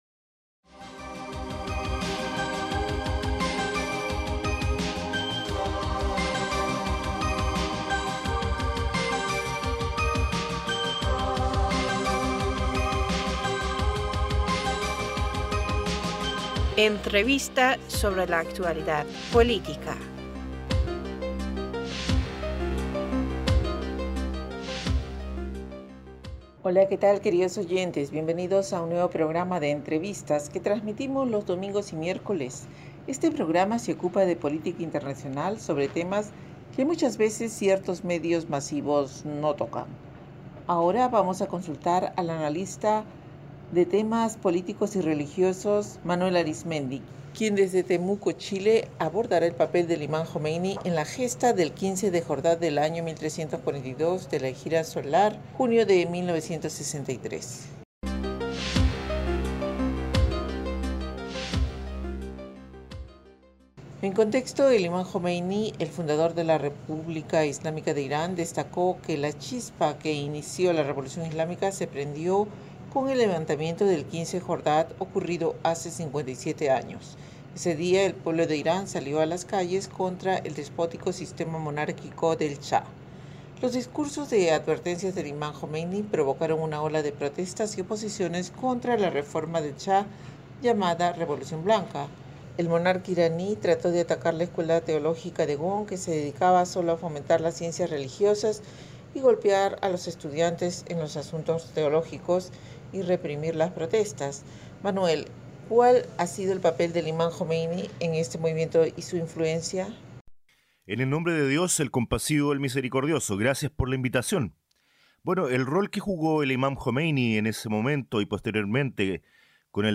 Entrevistador (E): Hola qué tal queridos oyentes bienvenidos a un nuevo programa de Entrevistas, que trasmitimos los domingos y miércoles; este programa se ocupa de política internacional sobre temas que muchas veces ciertos medios masivos no tocan.